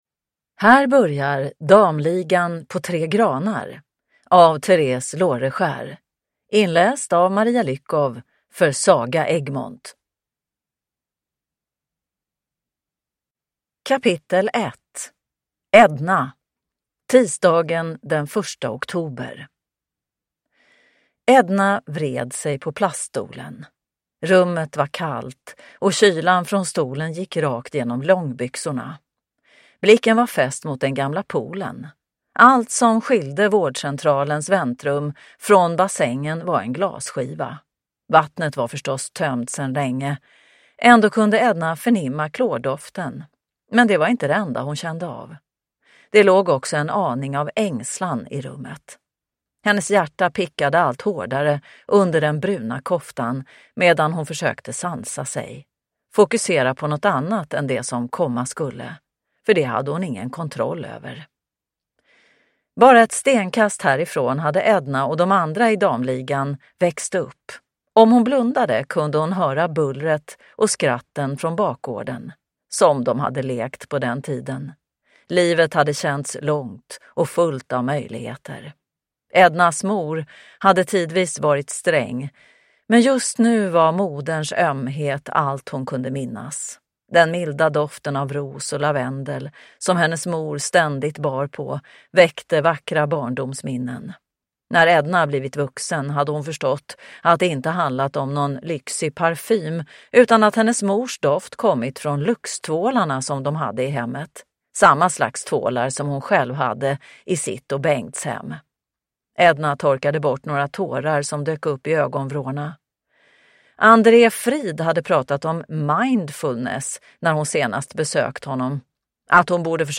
Damligan på Tre Granar – Ljudbok